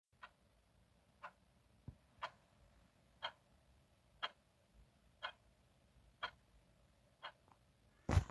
Clock Ticking
Ambient
Clock ticking.m4a